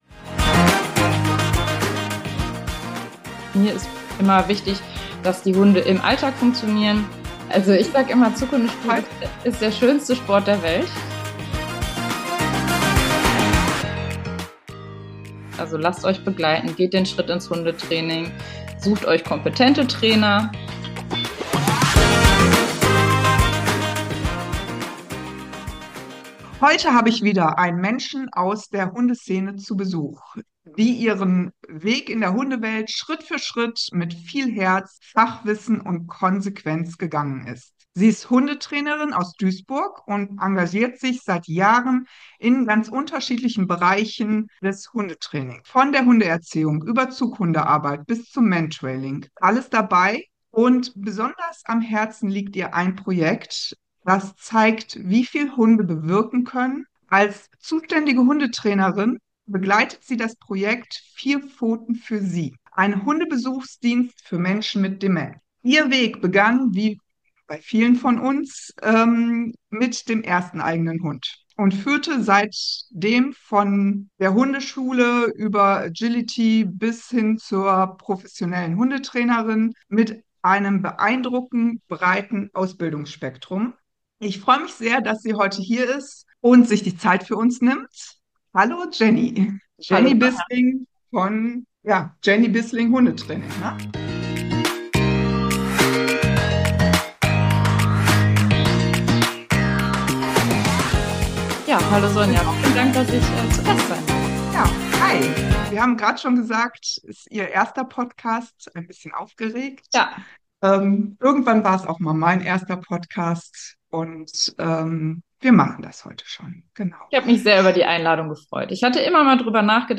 Ein Gespräch...